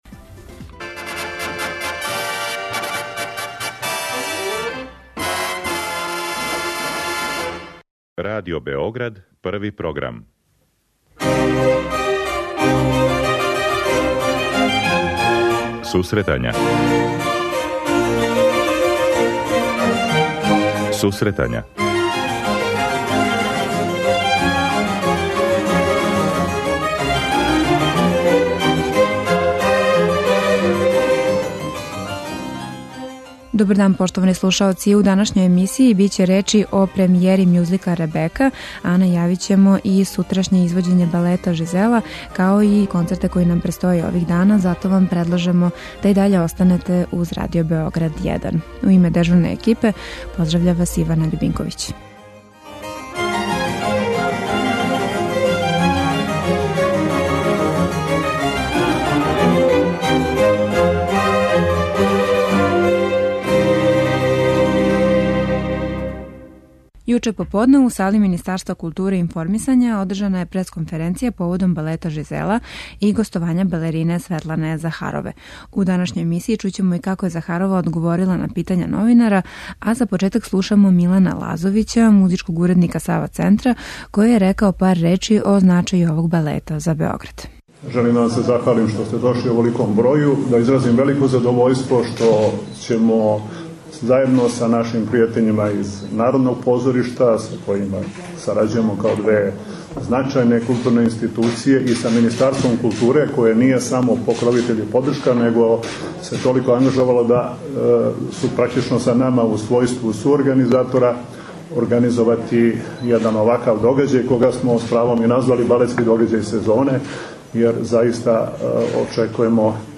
Централна тема емисије је извођење балета Жизела, 8.12. у Центру Сава. Чућемо шта је тим поводом рекла балерина Светлана Захарова. Биће речи и о премијери мјузикла Ребека, као и другим предстојећим концертима.